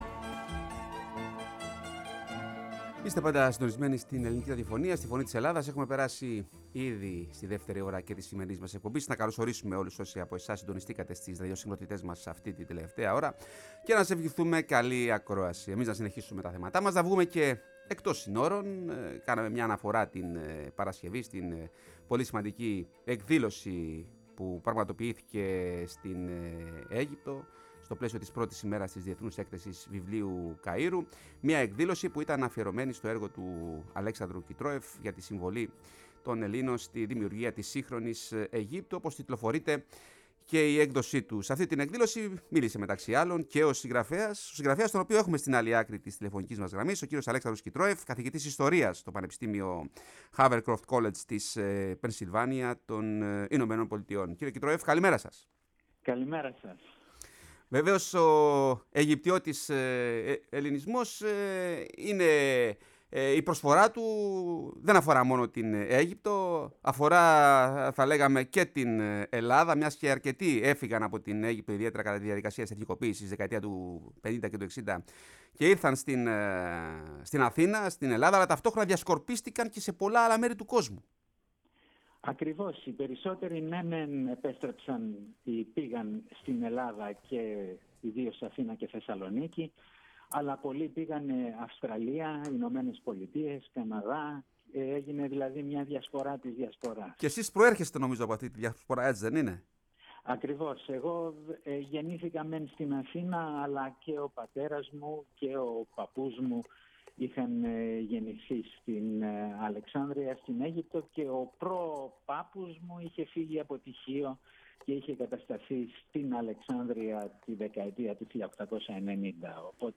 φιλοξενήθηκε στη Φωνή της Ελλάδας